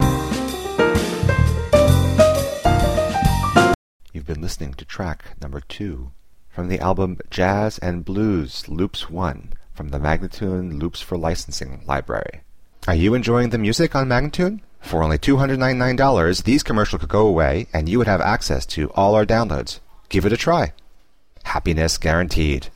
Instrumental samples in many genres.